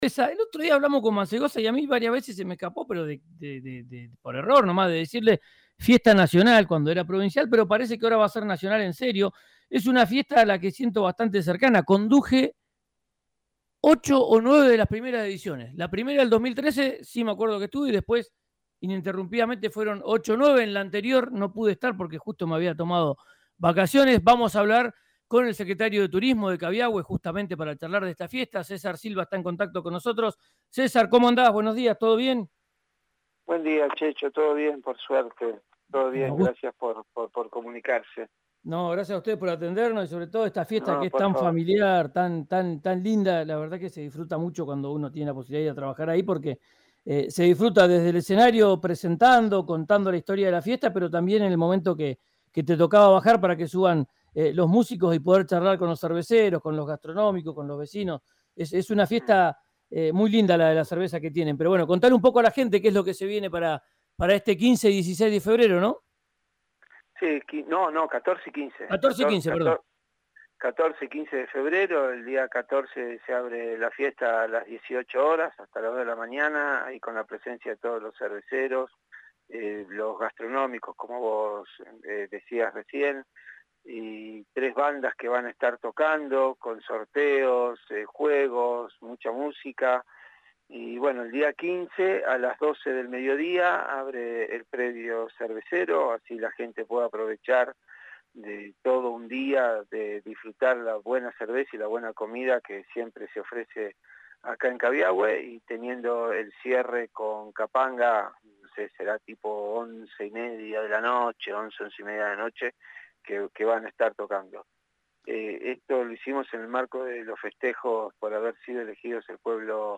en el aire de RÍO NEGRO RADIO